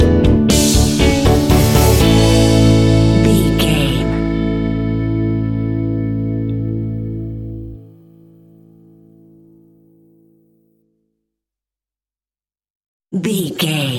Ionian/Major
energetic
uplifting
instrumentals
upbeat
groovy
guitars
bass
drums
piano
organ